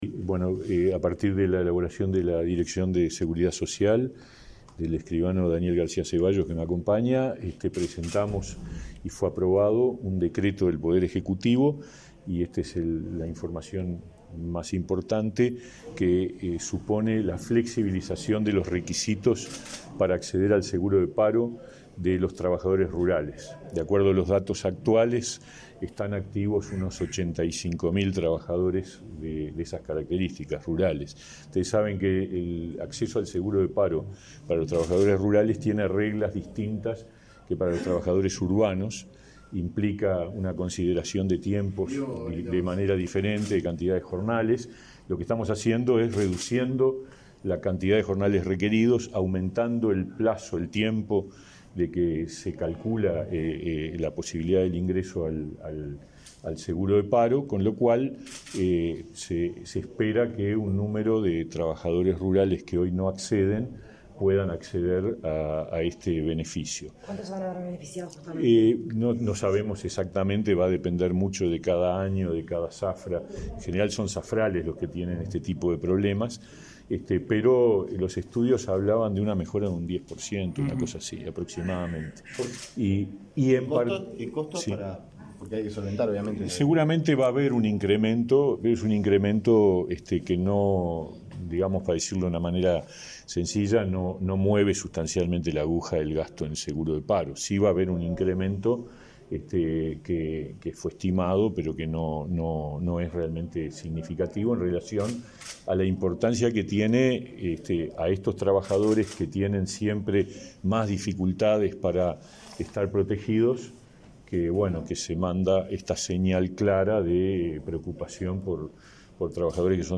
Declaraciones de prensa de autoridades del Ministerio de Trabajo
El ministro de Trabajo, Pablo Mieres, y el director nacional de Seguridad Social, Daniel García Zeballos, explicaron a la prensa la decisión de